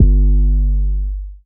MB 808 (7).wav